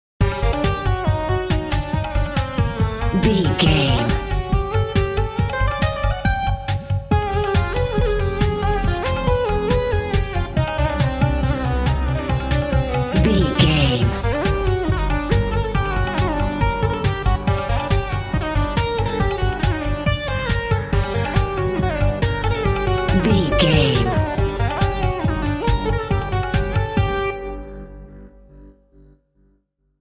Aeolian/Minor
tambourine
ethnic
mysterious music
hypnotic
drum machine